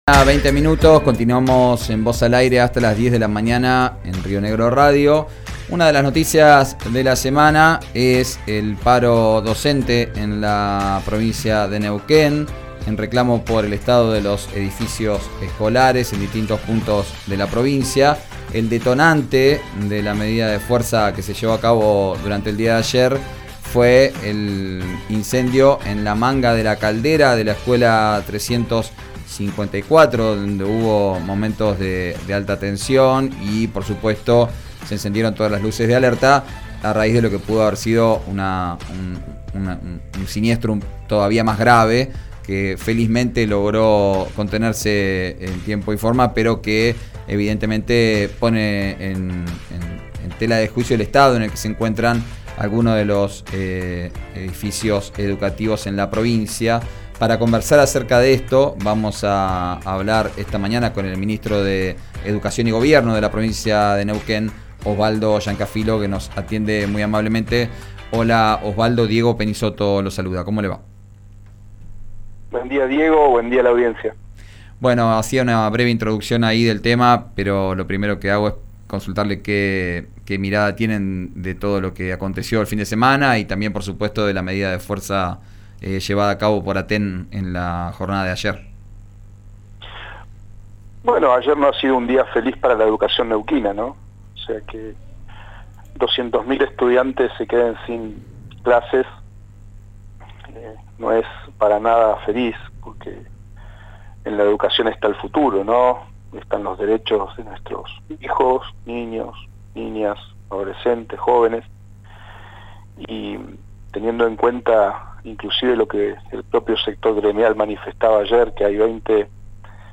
El ministro de Educación y Gobierno de Neuquén habló con RÍO NEGRO RADIO sobre el paro de ATEN y la denuncia sobre deficiencias edilicias.